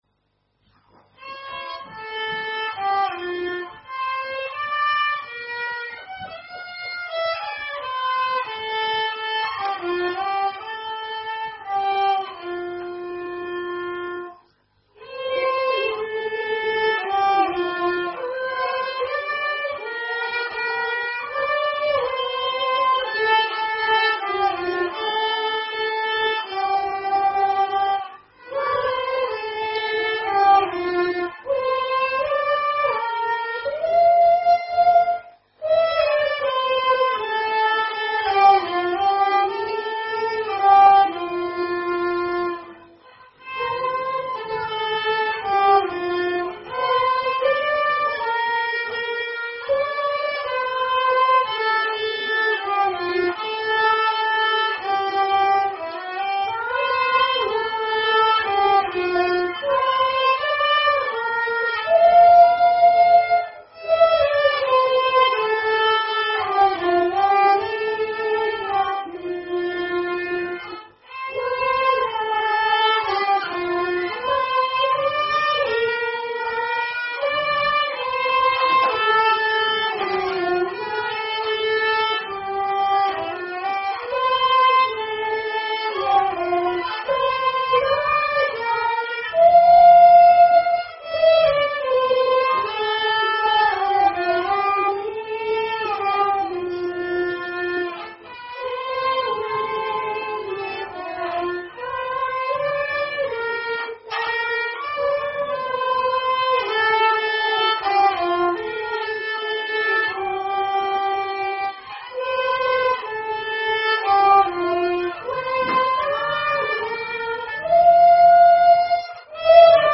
Special performances